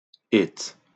akcentowane enPR: ĭt, IPA/ɪt/, SAMPA/It/